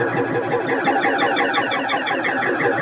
Index of /musicradar/rhythmic-inspiration-samples/85bpm
RI_DelayStack_85-08.wav